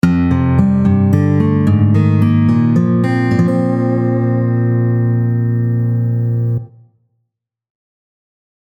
showoff-technique-1.mp3